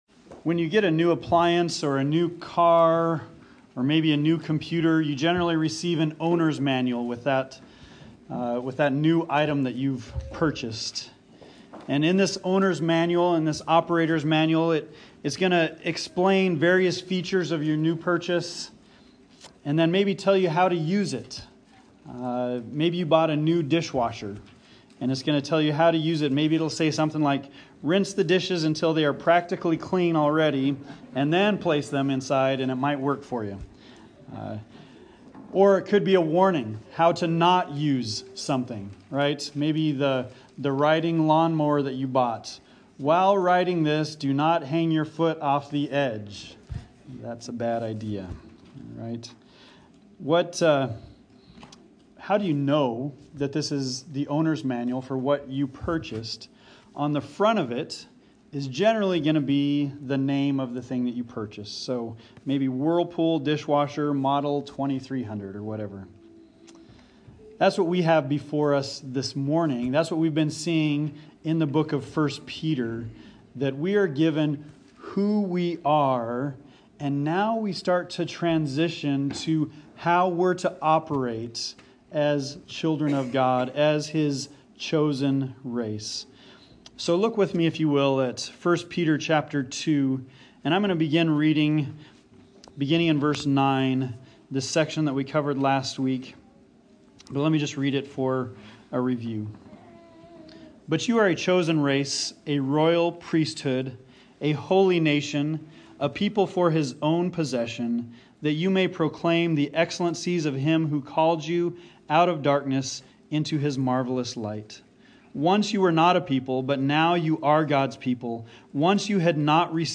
Bible Text: 1 Peter 2:11-12 | Preacher